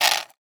crumch.wav